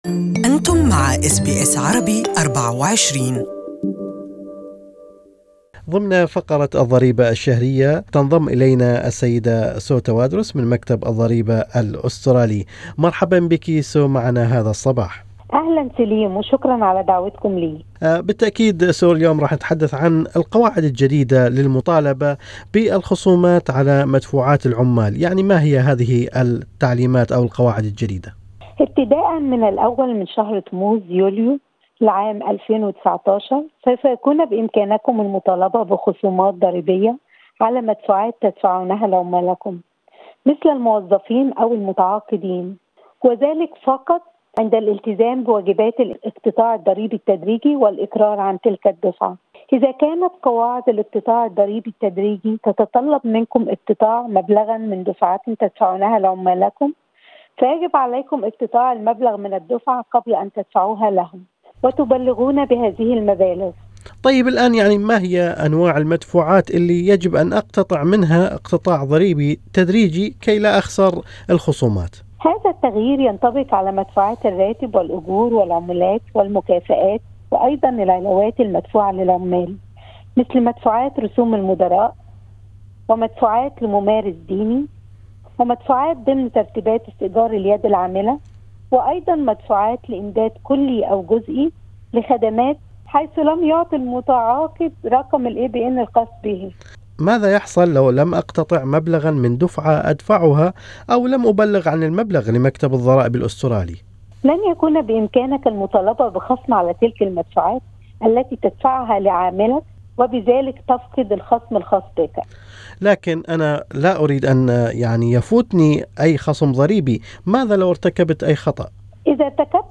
More is in this interview